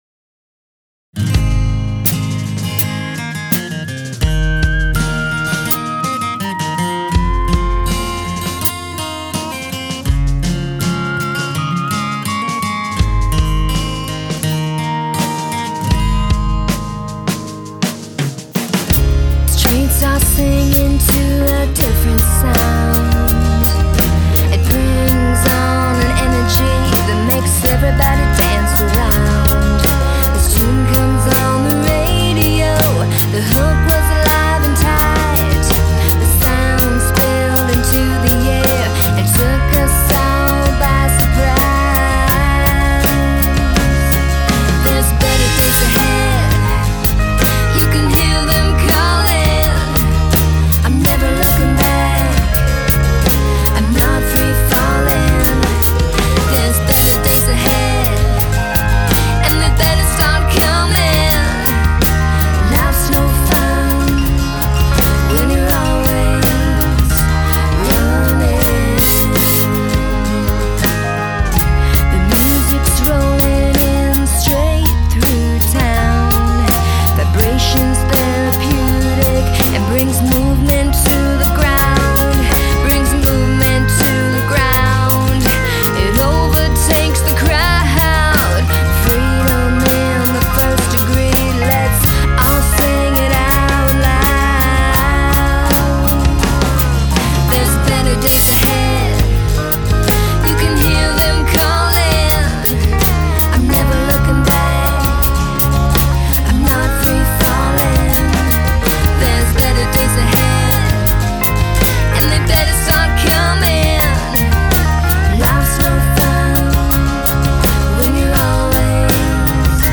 Rock Pop Rockabilly Reggae Ska Country Rock